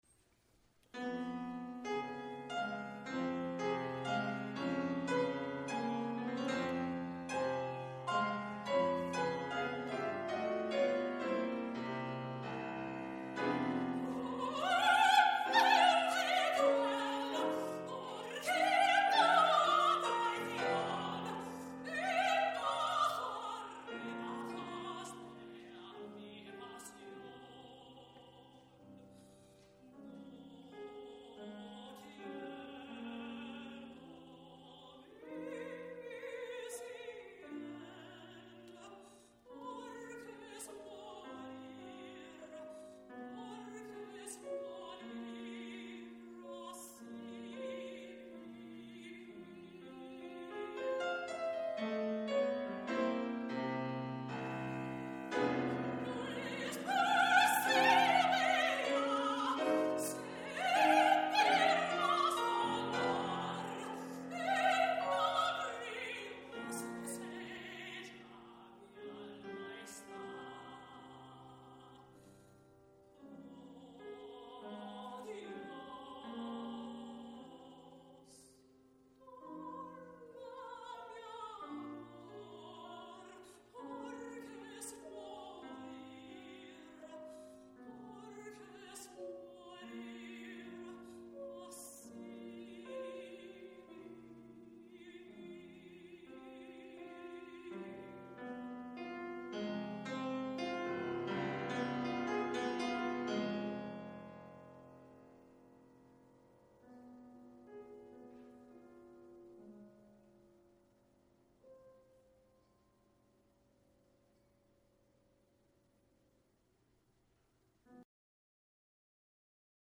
Recording, musical
Vocal Studies Presentations-Audio (UMKC)